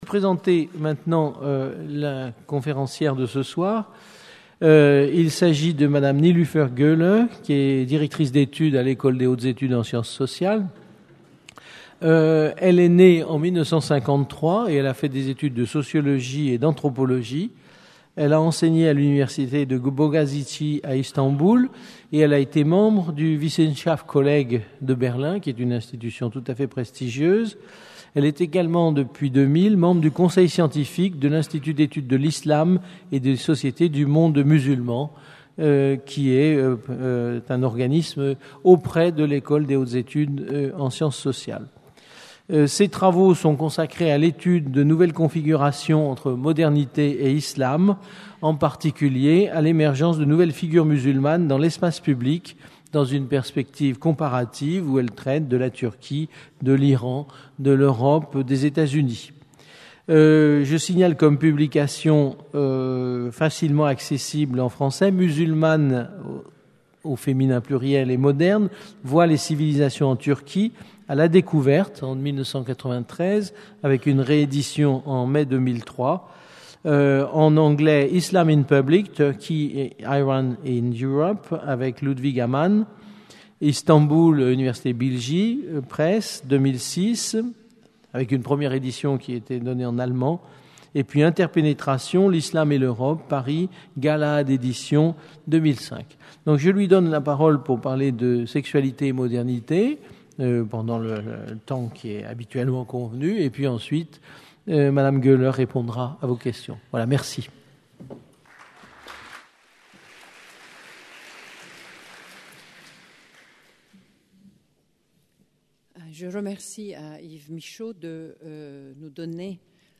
Sexualité et modernité par Nilüfer Gole Une conférence du cycle La Turquie, aujourd'hui demain